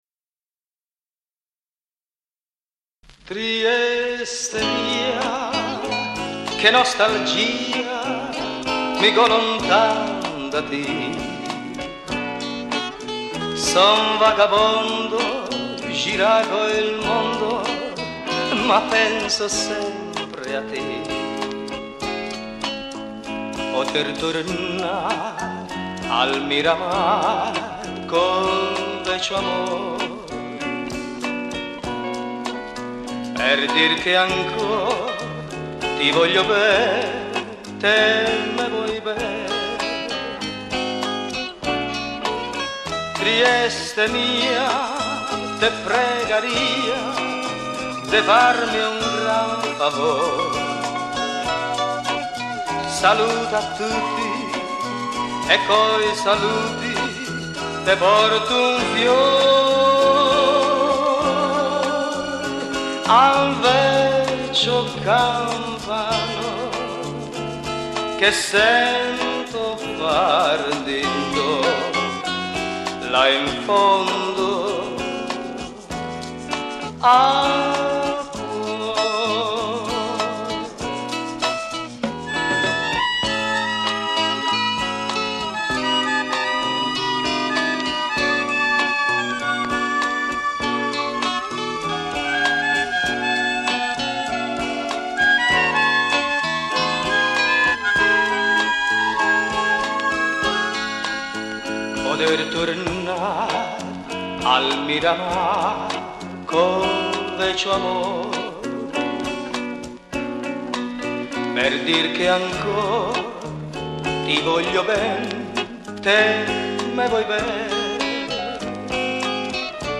DRUMS
GUITARS
TASTIERE